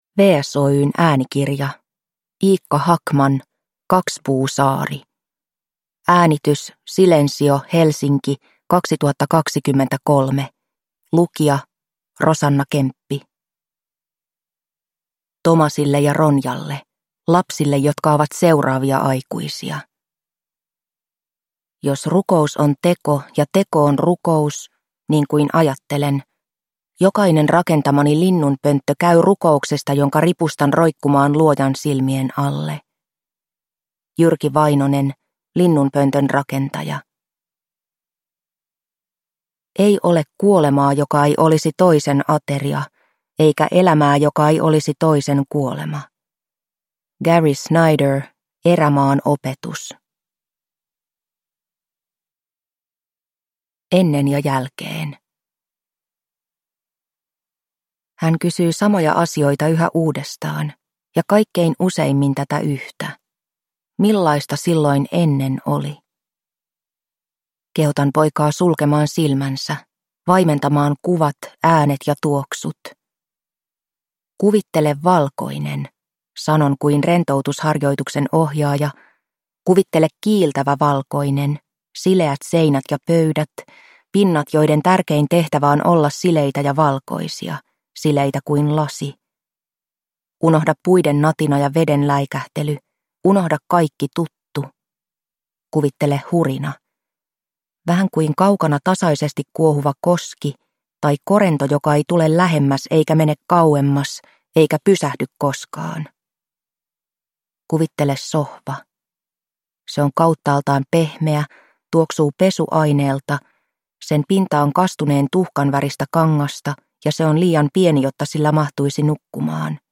Kakspuusaari – Ljudbok – Laddas ner